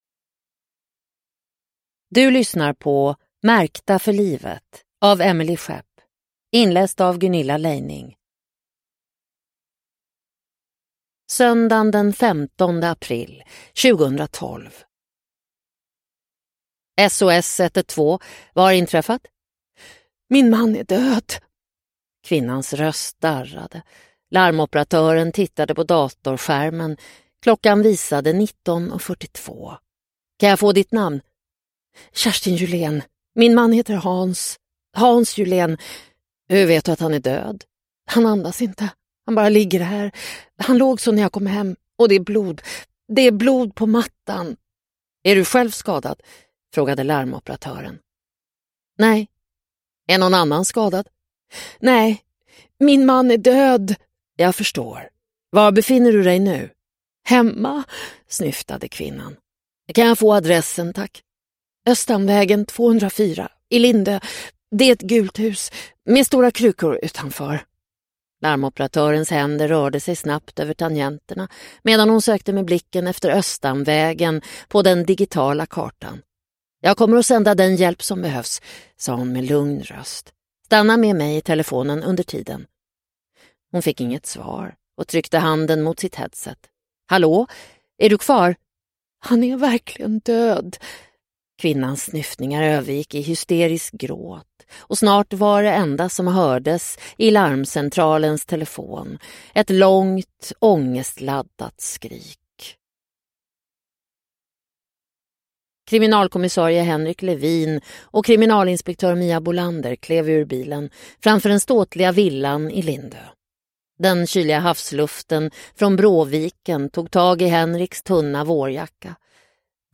Märkta för livet – Ljudbok – Laddas ner